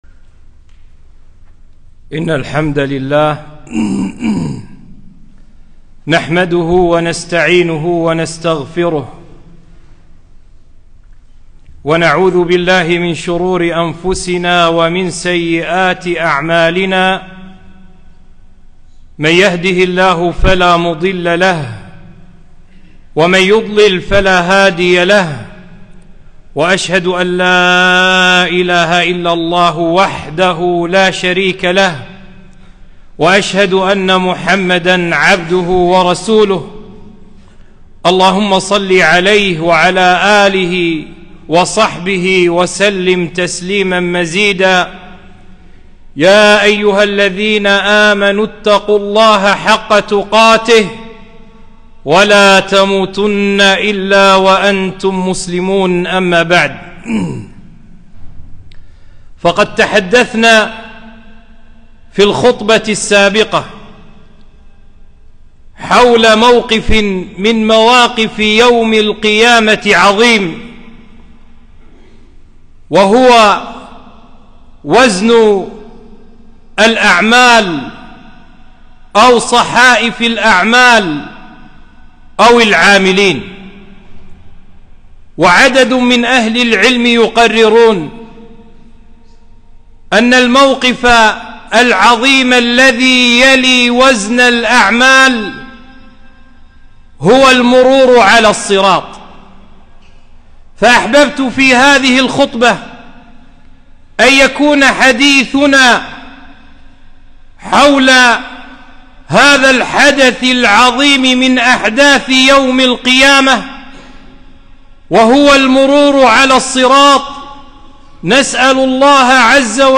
خطبة - وصف الصراط والمرور عليه